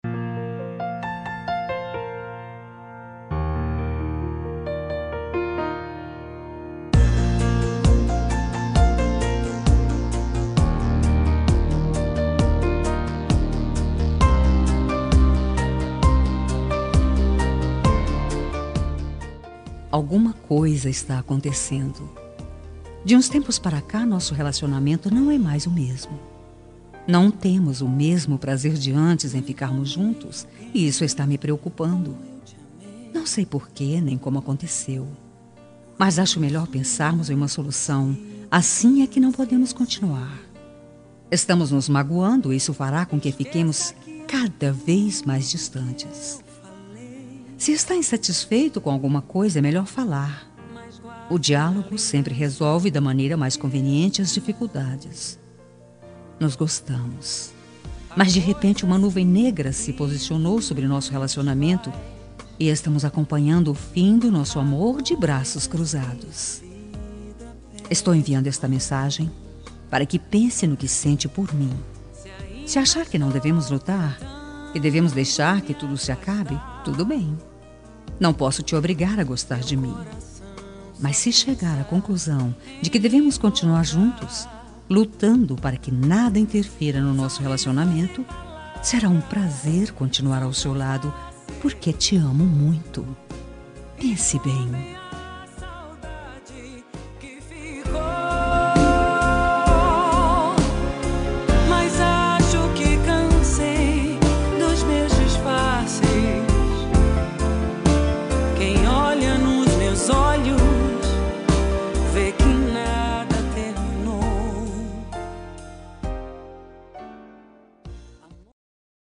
Telemensagem Relacionamento Crise – Voz Feminina – Cód: 5435